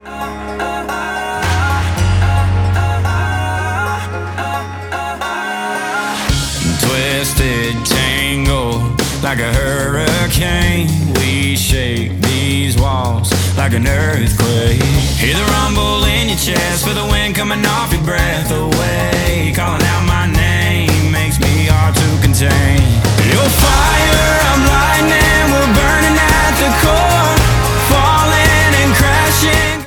• Country